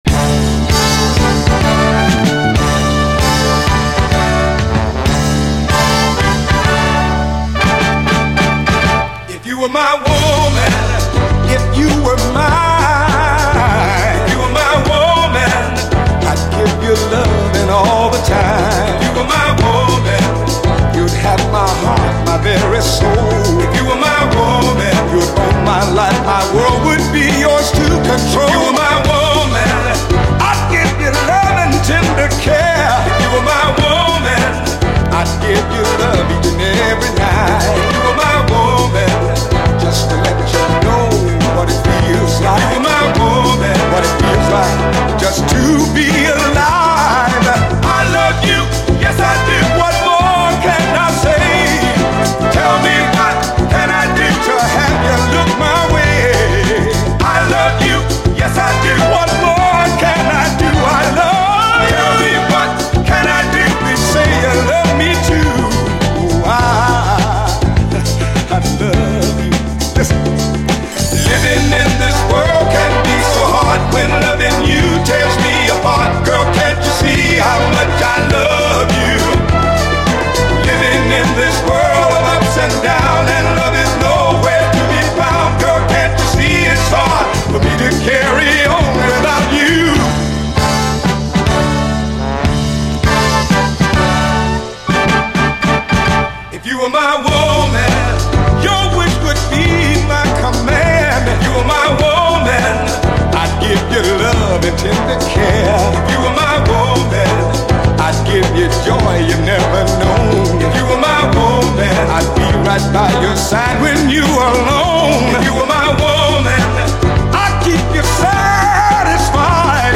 SOUL, 70's～ SOUL, 7INCH
ゴージャスに舞う最高モダン・ソウル45！
ゴージャス＆エレガントな最高モダン・ソウル・ダンサー！
「(INSTRUMENTAL)」